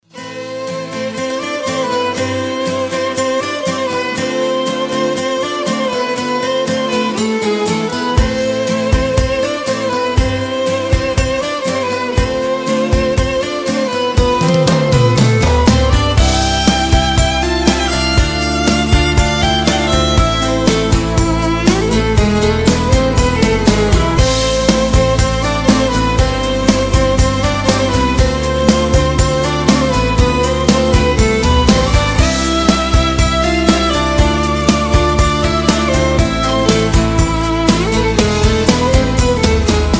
• Качество: 128, Stereo
без слов